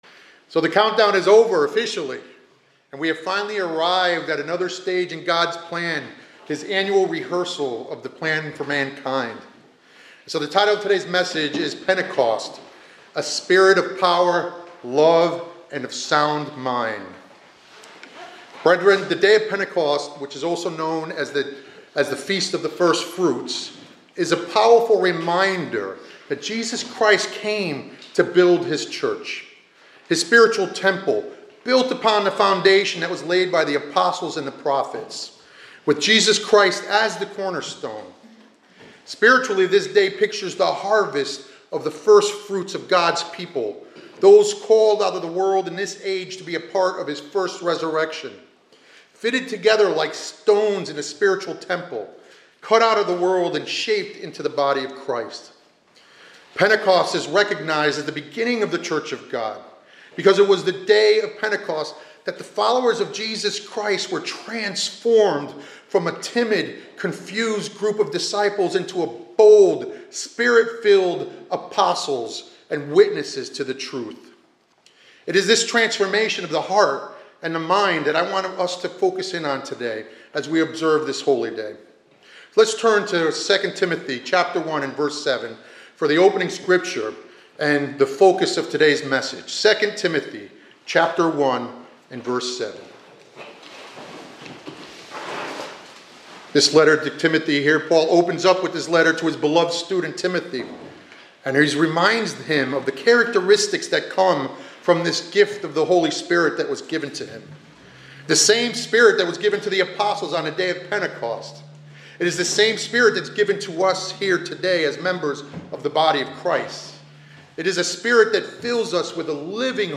This sermonette was given on the Day of Pentecost, focuses on the themes of power, love, and a sound mind as gifts of the Holy Spirit. It emphasizes the significance of Pentecost as the beginning of the Church of God and the transformation of the Apostles from fear to boldness through the Holy Spirit.